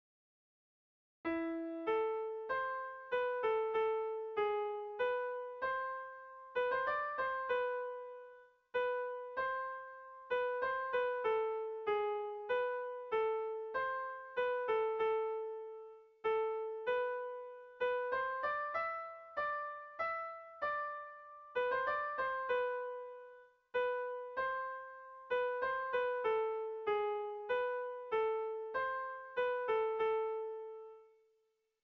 Erlijiozkoa
Zortziko txikia (hg) / Lau puntuko txikia (ip)
ABDE